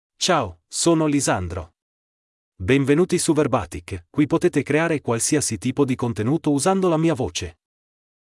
LisandroMale Italian AI voice
Lisandro is a male AI voice for Italian (Italy).
Voice sample
Male
Lisandro delivers clear pronunciation with authentic Italy Italian intonation, making your content sound professionally produced.